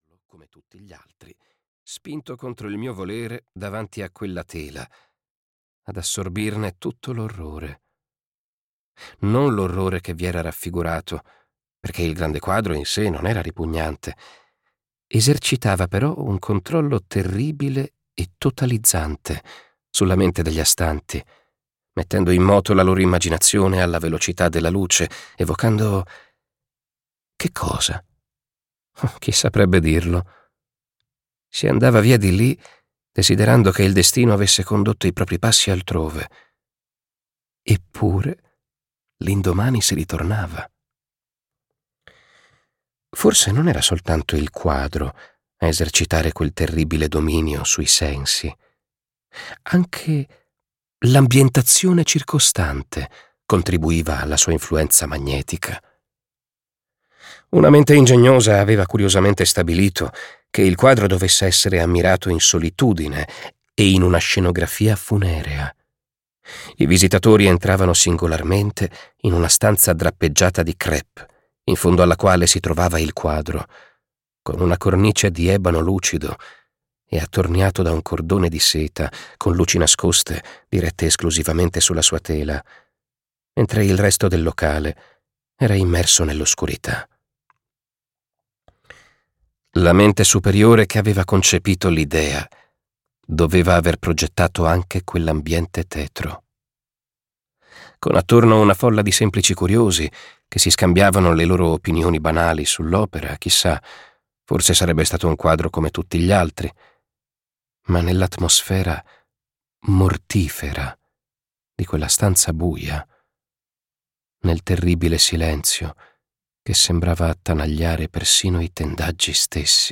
"Notturno" di AA.VV. - Audiolibro digitale - AUDIOLIBRI LIQUIDI - Il Libraio